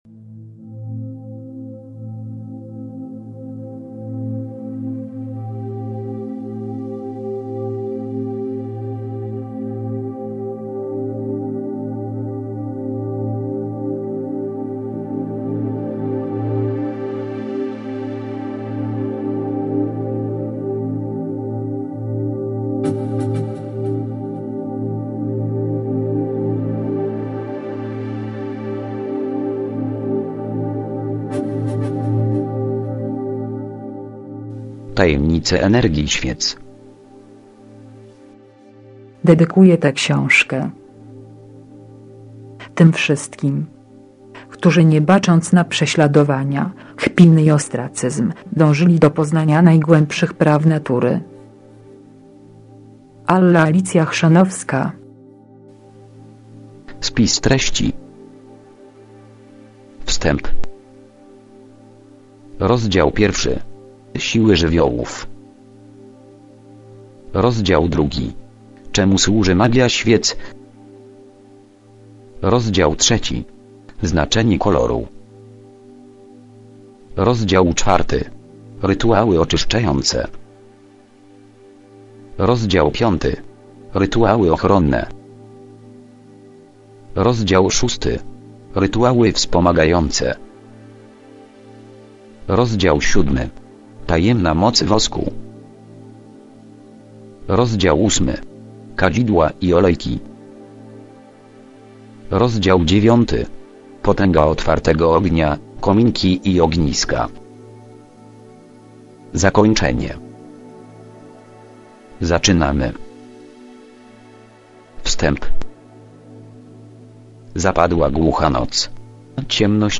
Przyk�adowy fragment Opis Ksi��ka do s�uchania.